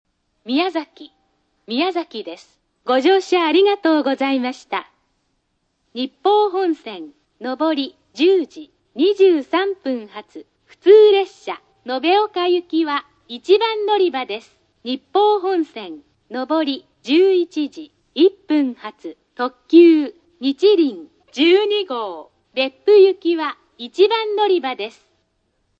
駅の音
スピーカー：ソノコラム
音質：B
到着+のりかえ放送　　(126KB/25秒)
特徴的な放送として、「到着放送」の”ご乗車ありがとうございました”のフレーズが別府と同様で、「ご乗車」のあとにワンテンポ無く、スムーズに流れます。
音割れ等無く、九州内では綺麗な部類の音質です。